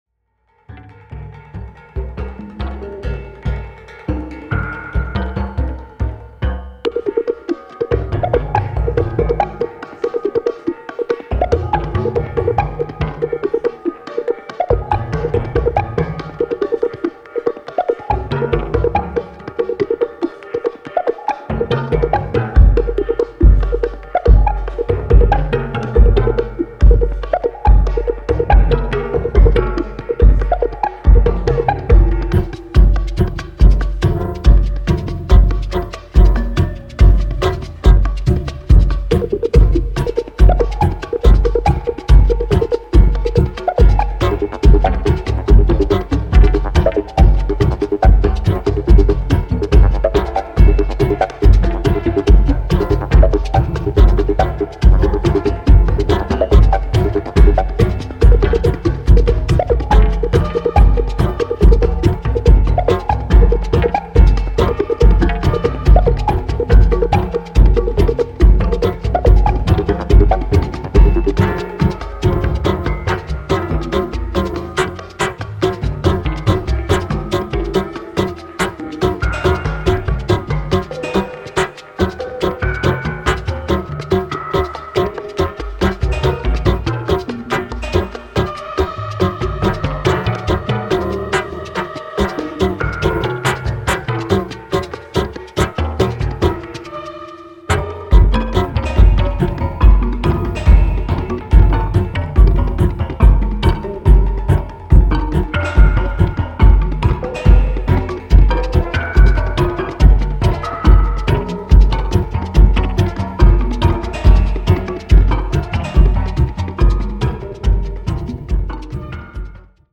ガムランの豊穣な構造と音響をモダン・ミニマルの領域に引き寄せた、多様なレイヤーが織りなすポリリズムのマジカルな世界。